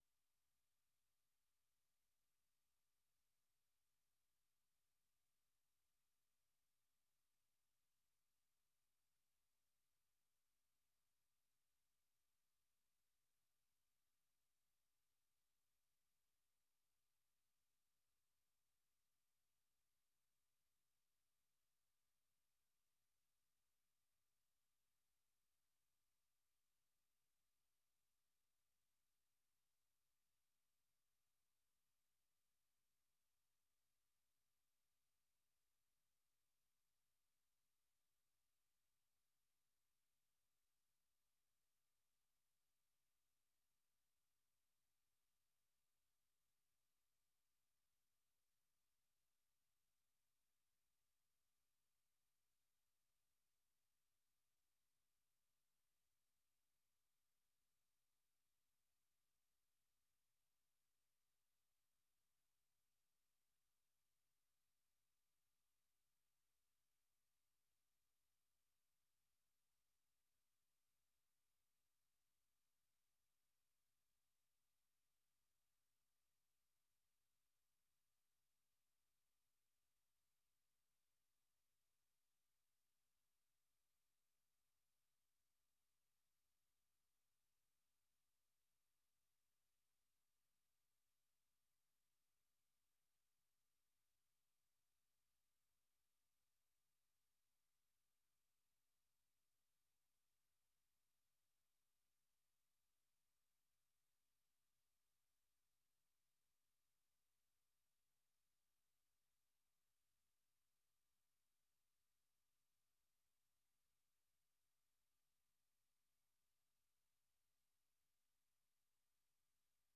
1 Learning English Podcast - December 09, 2025 29:56 Play Pause 43m ago 29:56 Play Pause Play later Play later Lists Like Liked 29:56 Learning English use a limited vocabulary and are read at a slower pace than VOA's other English broadcasts.